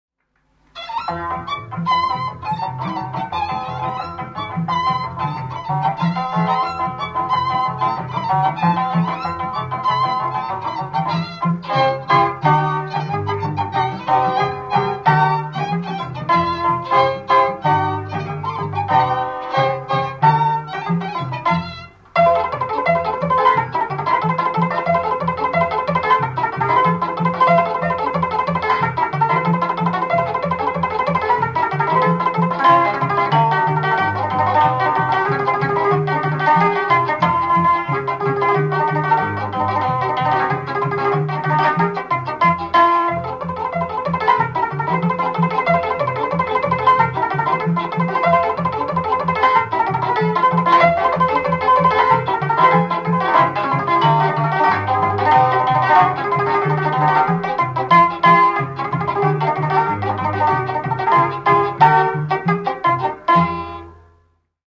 Publikált népzenei felvételek -- On-line adatbázis
Műfaj Csárdás
Hangszer Zenekar
Helység Sopron